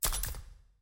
sound_click.mp3